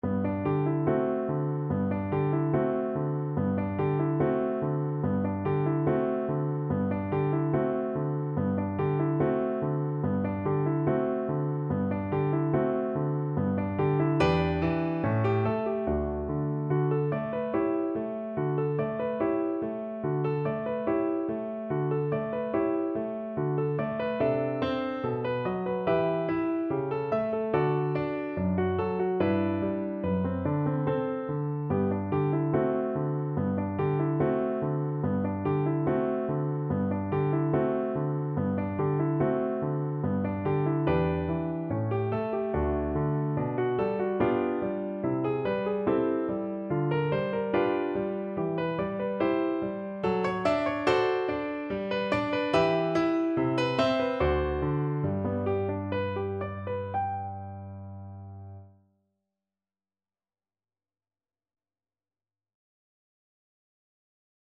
Allegretto moderato =72
2/4 (View more 2/4 Music)
G major (Sounding Pitch) (View more G major Music for Violin )
Classical (View more Classical Violin Music)